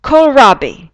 kohlrabi [‚kəul’ra:bi]
kohlrabi.wav